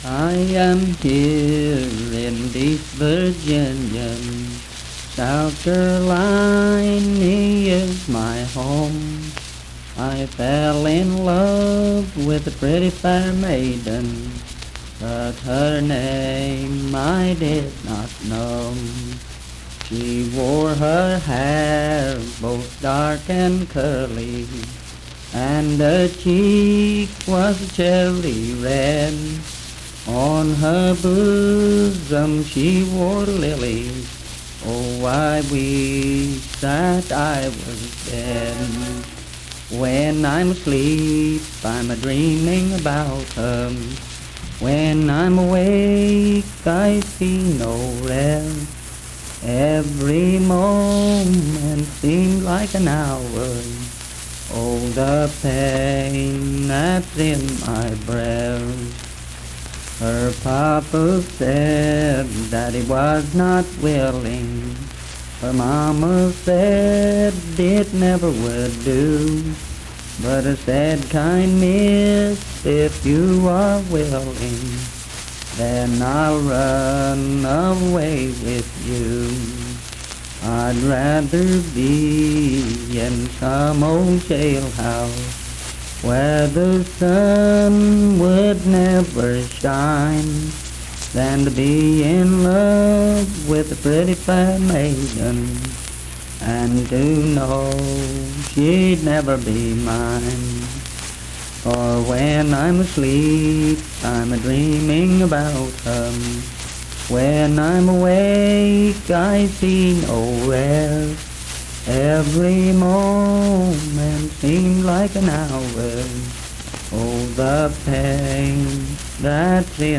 Unaccompanied vocal performance
Voice (sung)